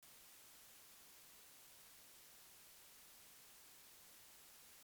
Шумит звуковая карта
Всем привет, недавно я купил новый микрофон и заметил больше шума в записях.